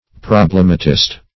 Problematist \Prob"lem*a*tist\, n. One who proposes problems.